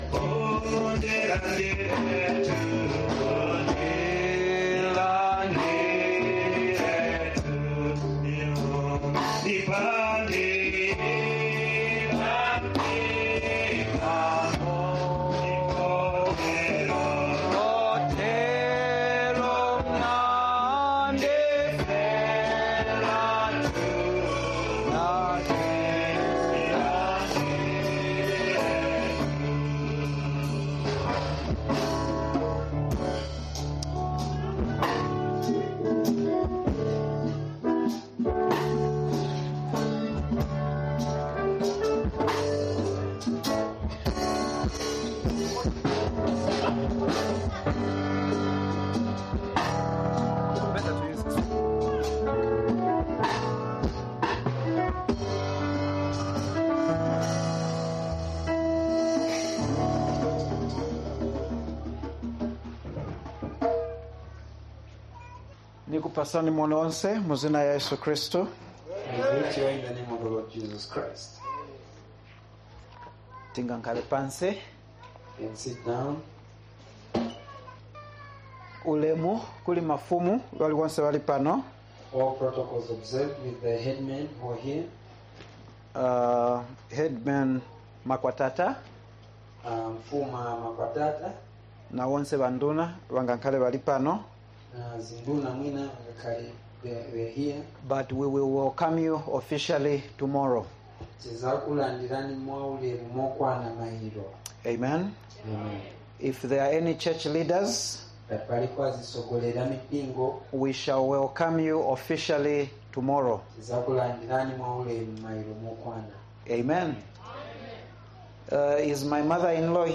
Mtenguleni Crusade - First Service | August 29, 2025